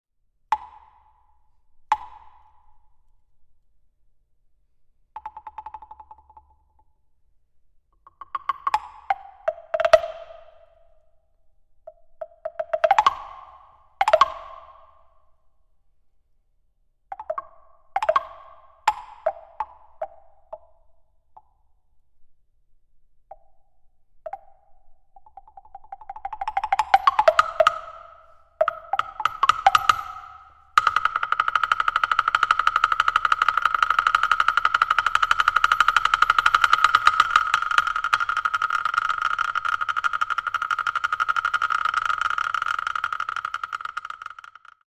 microtonal, electro-acoustic, and extended timbral contexts
electronics
percussion